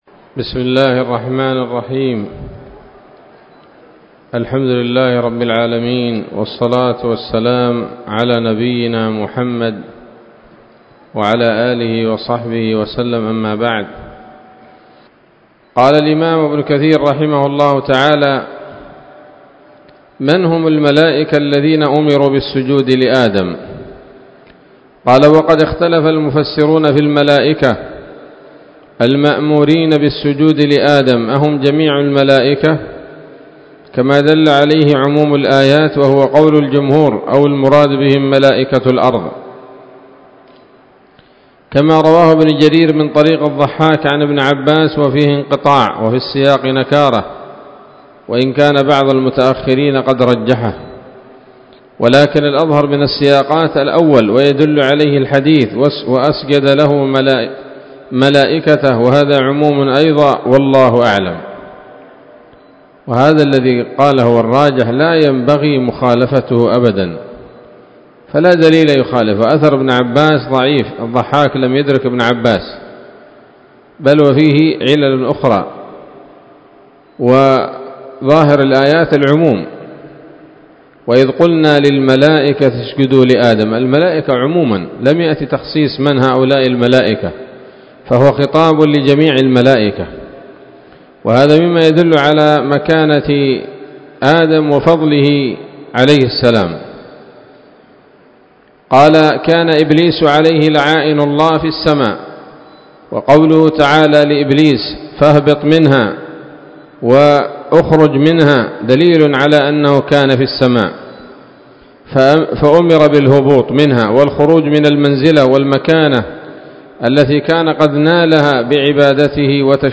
الدرس الرابع من قصص الأنبياء لابن كثير رحمه الله تعالى